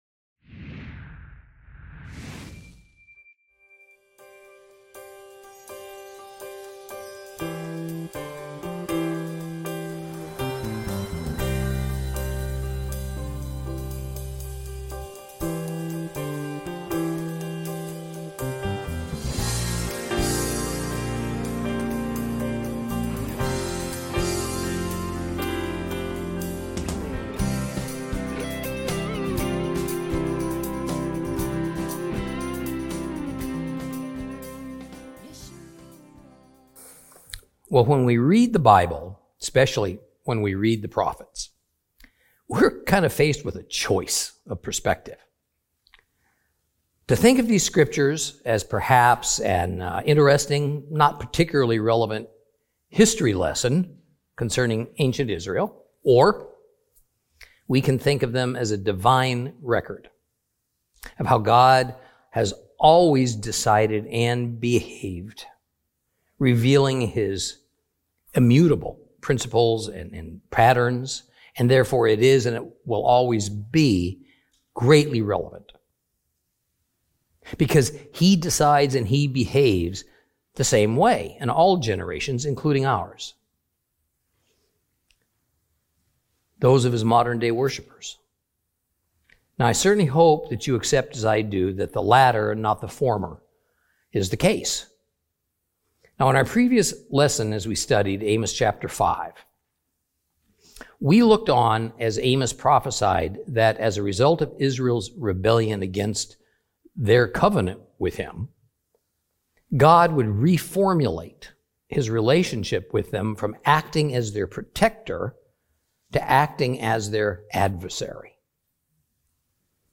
Teaching from the book of Amos, Lesson 9 Chapters 5 and 6.